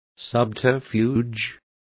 Complete with pronunciation of the translation of subterfuges.